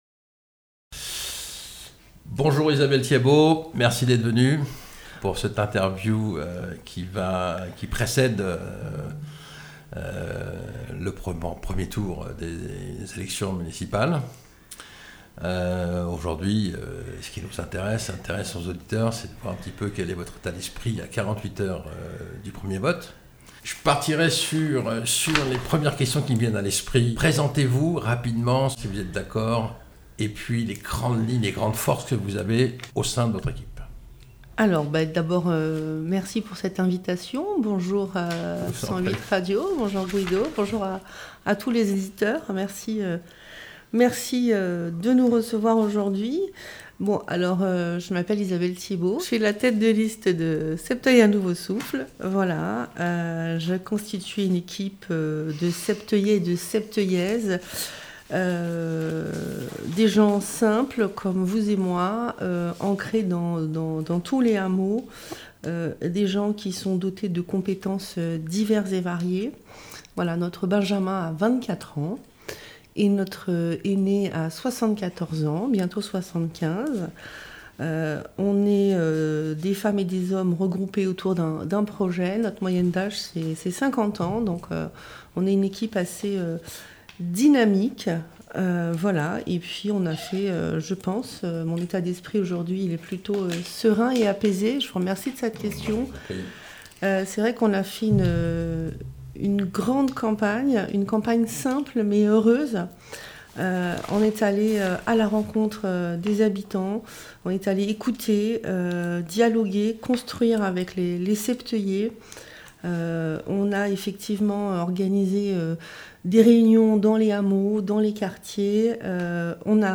13 mars 2026 - 89 vues Écouter le podcast Télécharger le podcast Chers Auditeurs. 108 radio a décidé de couvrir la dernière ligne droite des municipales à Septeuil. Nous avons sollicité les trois têtes de liste pour un interview exclusif.